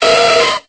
Cri de Dynavolt dans Pokémon Épée et Bouclier.